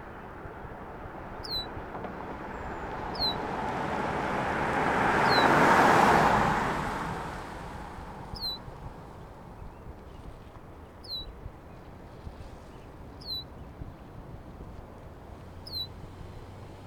Spring trip to Yosemite Valley
Birds even sing to a car on the road
carPassing.ogg